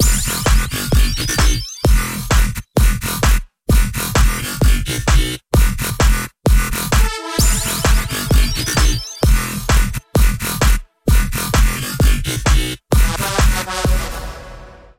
For example, if you choose the EDM genre, you can generate a clip with a strong baseline.